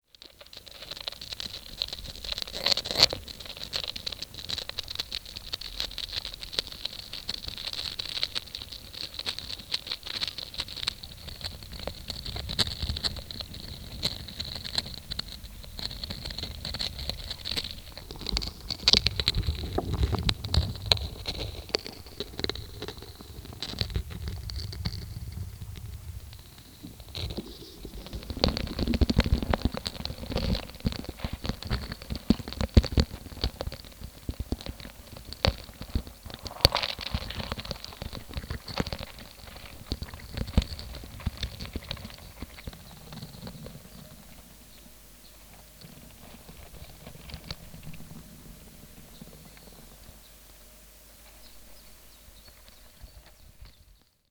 Муравьи в сахаре прекрасная подложка к любому тречку ) Вложения antsonsugar.mp3 antsonsugar.mp3 2,1 MB · Просмотры: 882